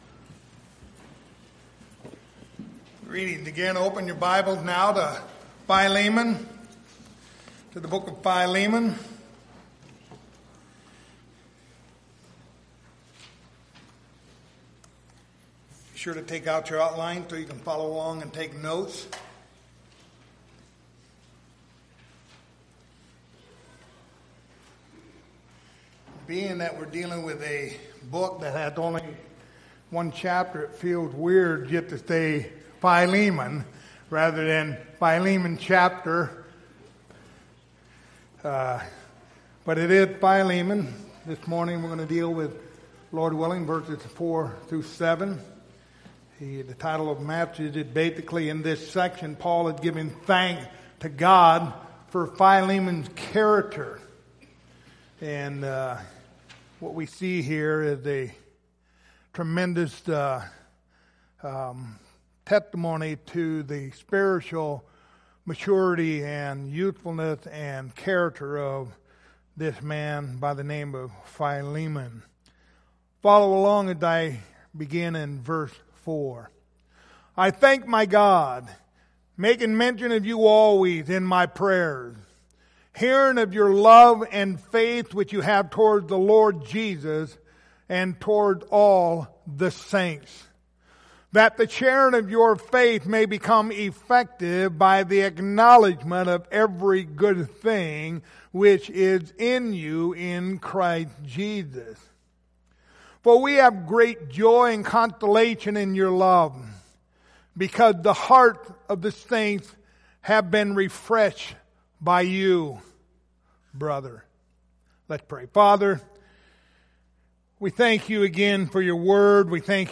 Passage: Philemon 1:4-7 Service Type: Sunday Morning